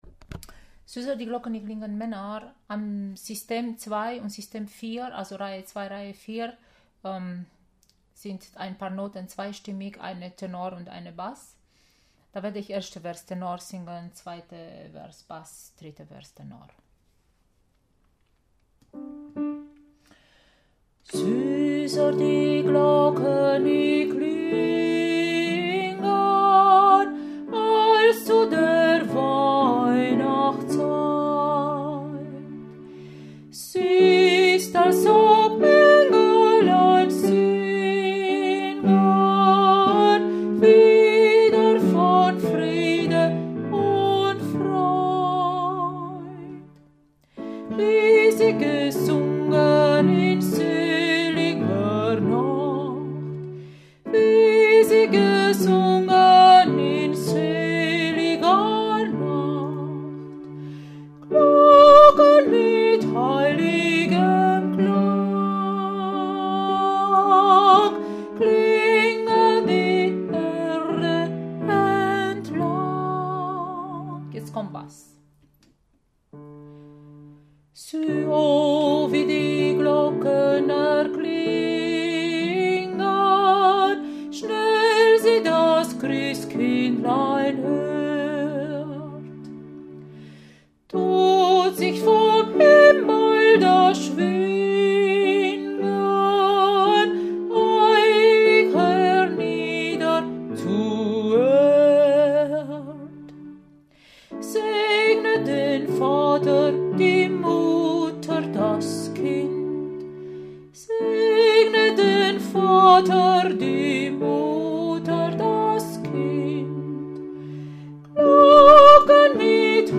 Süßer die Glocken Tenor + Bass
Süsser-die-Glocken-Männer.mp3